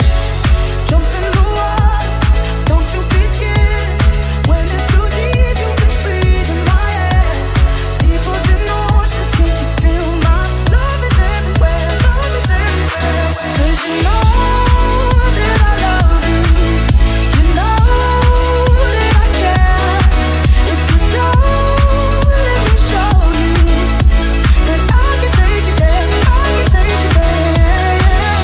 Genere: pop,dance,deep,disco,house.hit